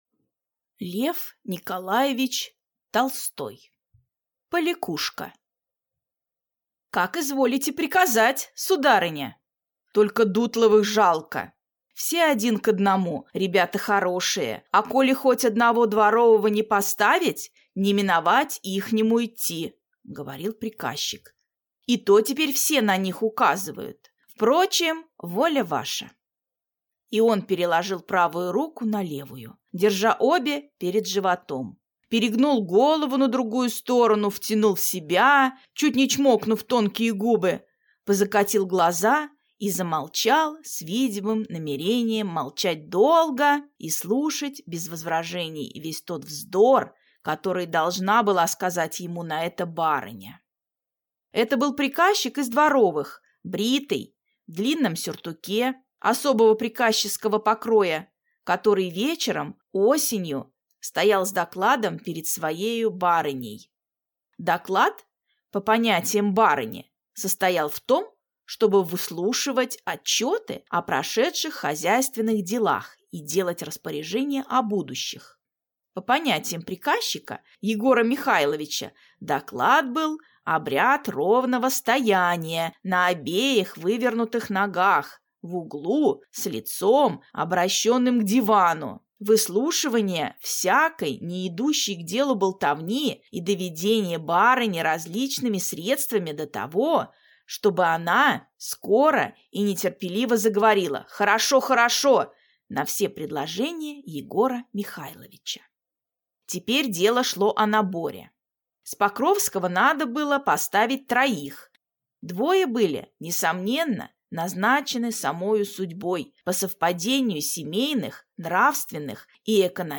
Аудиокнига Поликушка | Библиотека аудиокниг